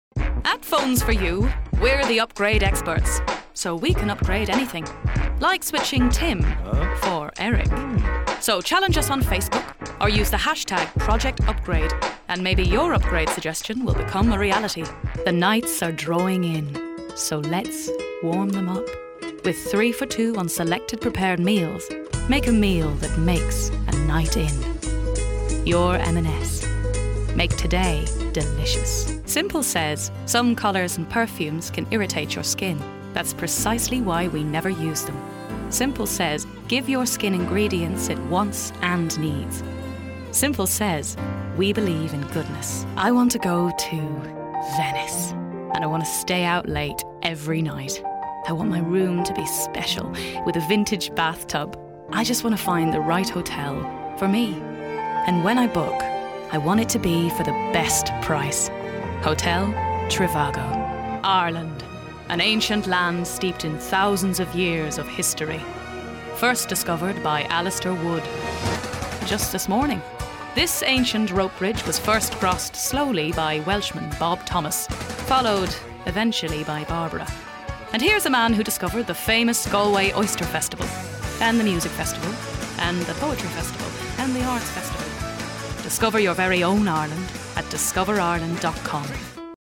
Female
Home Studio Setup
20s/30s, 30s/40s
English Neutral, Irish Neutral